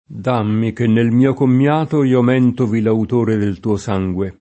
mentovare v.; mentovo [m$ntovo] — es. con acc. scr.: dammi che nel mio commiato Io mèntovi l’autore del tuo sangue [
d#mmi ke nnel m&o kommL#to io m$ntovi l aut1re del tuo S#jgUe] (D’Annunzio)